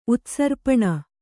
♪ utsarpaṇa